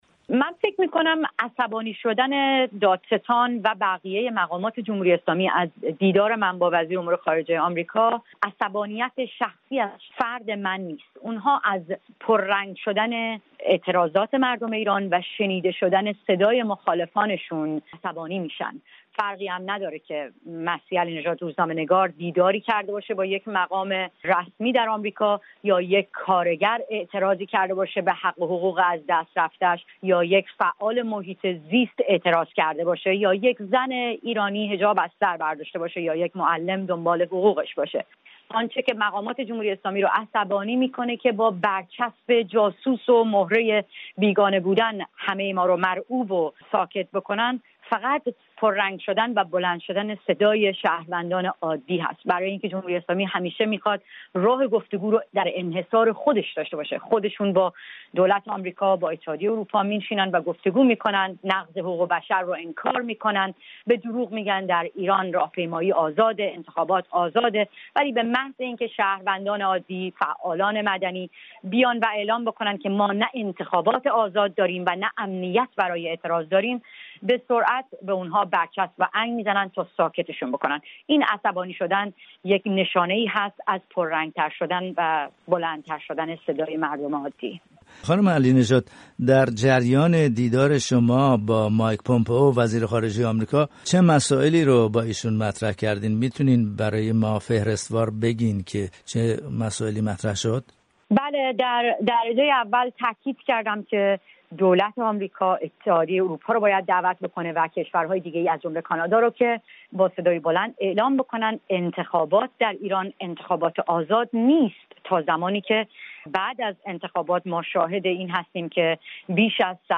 دادستان کل ایران با حمله به مسیح علی‌نژاد،‌ فعال سیاسی و حقوق زنان به خاطر دیدارش با مایک پومپئو،‌ وزیر خارجه آمریکا، او را با لحنی اهانت‌آمیز مورد خطاب قرار داده است. در گفت‌وگو با مسیح علی‌نژاد در واشینگتن، ‌ابتدا واکنش او را نسبت به گفته‌های دادستان کل ایران پرسیده‌ایم.